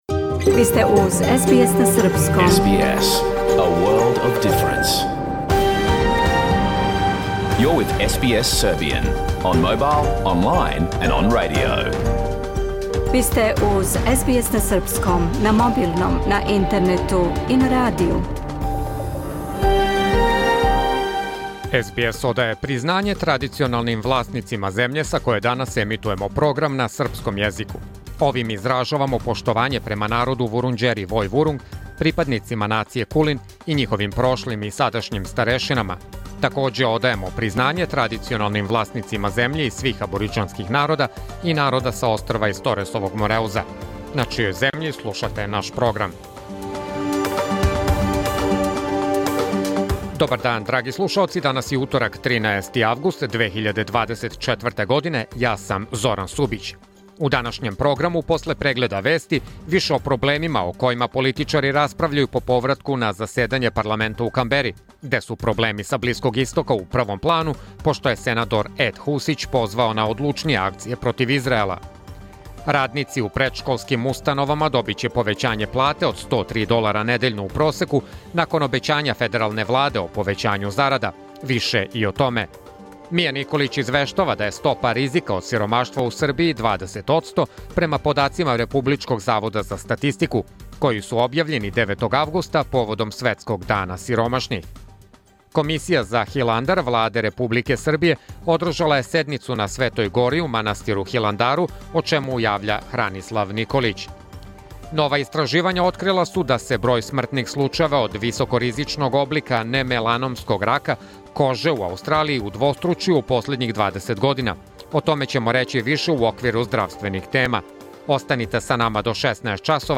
Програм емитован уживо 13. августа 2024. године